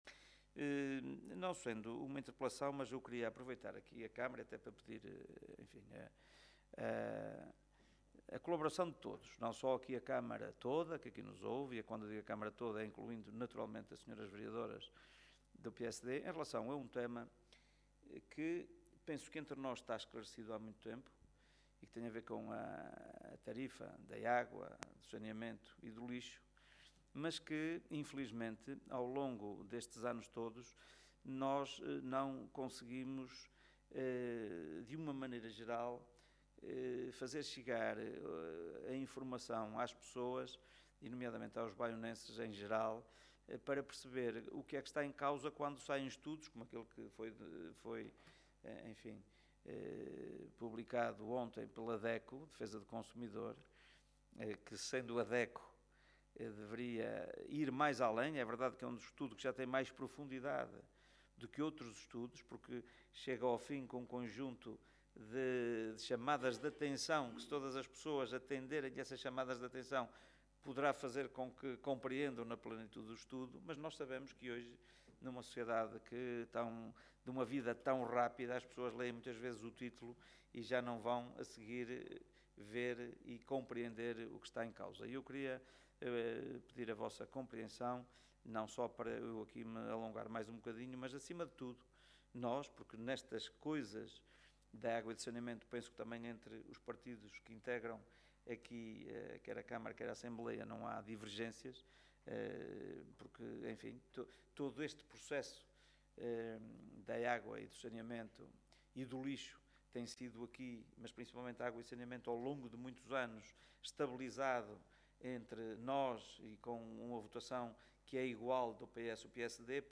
Agua-reuniao-27.11.mp3